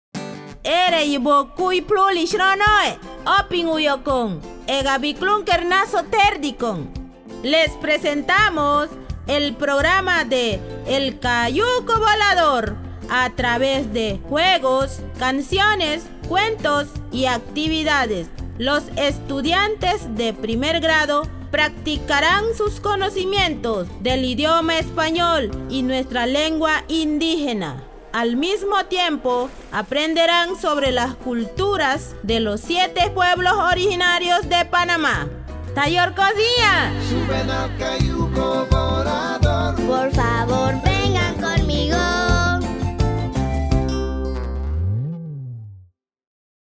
EPO Radio Spot in Naso
naso radio spot_final.wav